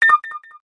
Alert.ogg